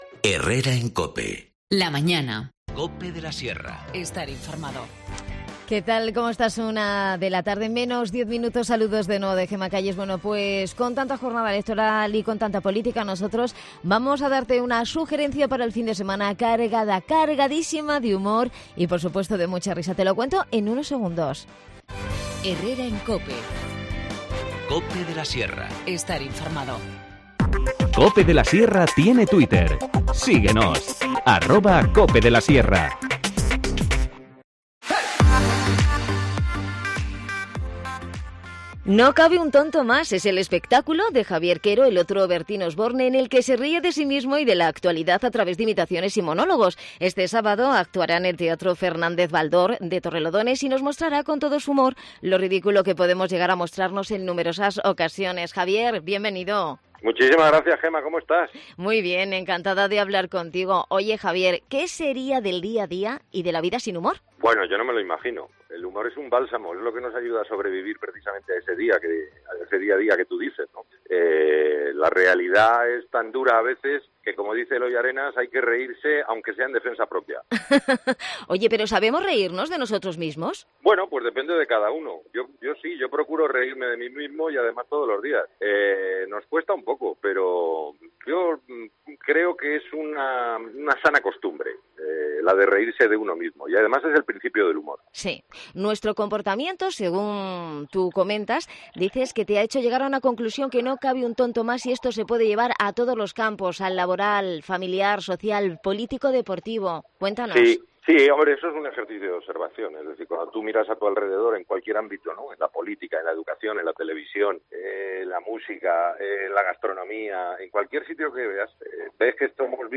Hemos hablado con el humorista, presentador, guionista y actor Javier Quero, el otro Bertín Osborne, sobre su espectáculo "No cabe un tonto más". Este sábado actúa en el Teatro Fernández-Baldor de Torrelodones.